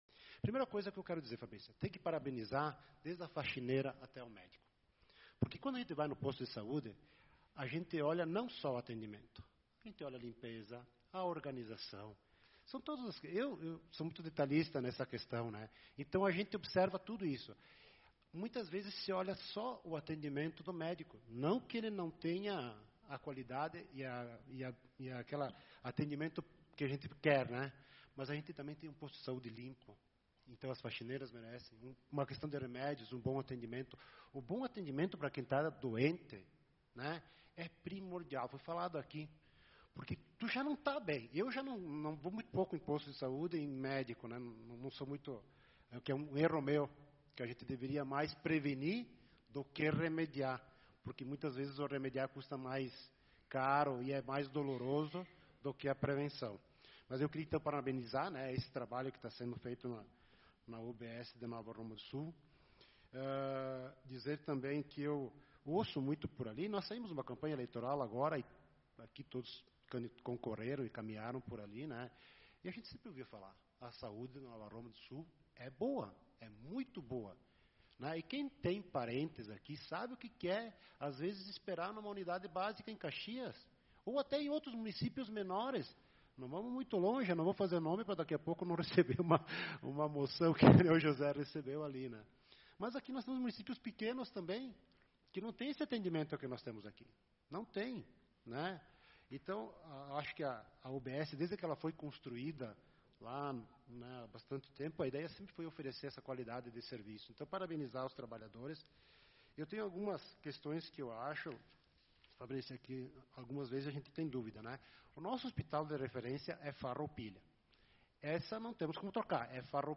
Sessão Ordinária do dia 21/05/2025